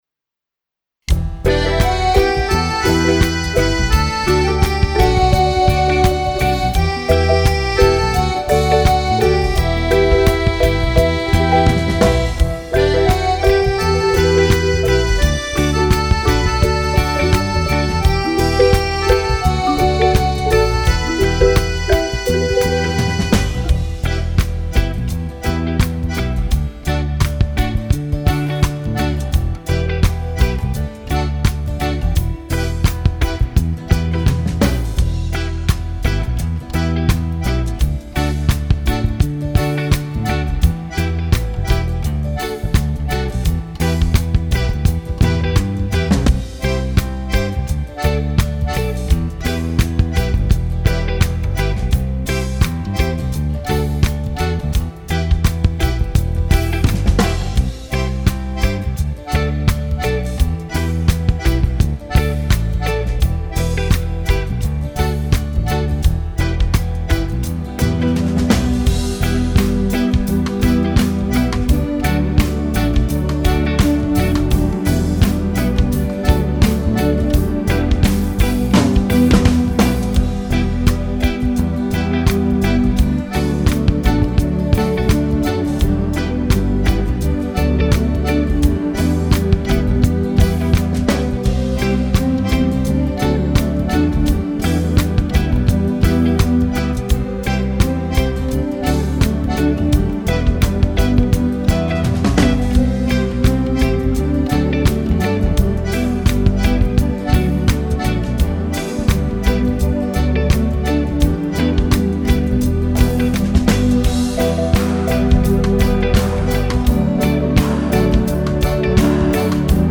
Version instrumentale avec chœur*